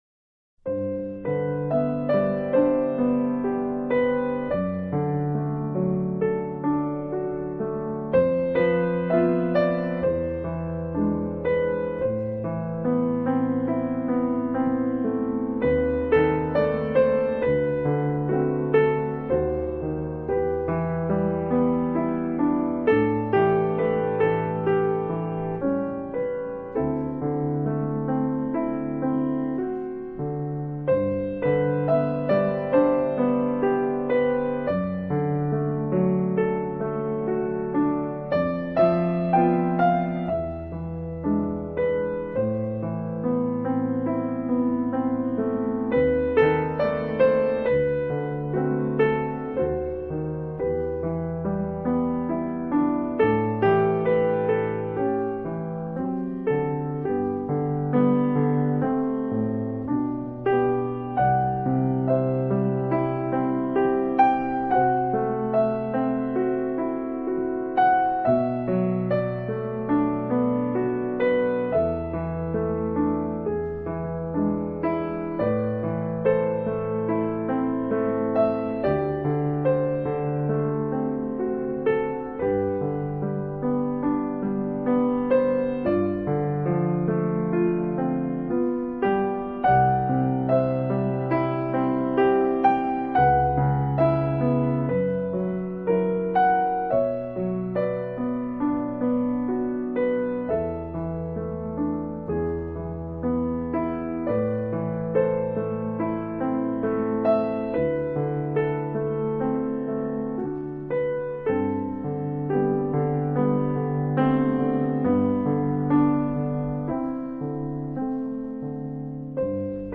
【钢琴专辑】
流派: NEWAGE
有种古典融合流
行的双重享受，结合了法式浪漫与英式的风情。